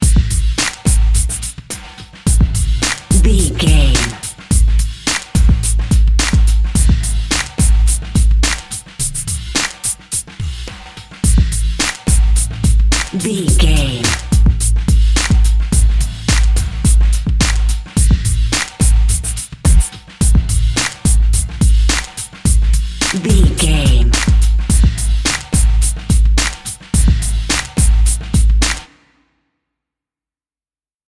Aeolian/Minor
drum machine
synthesiser
hip hop
Funk
neo soul
energetic
bouncy
funky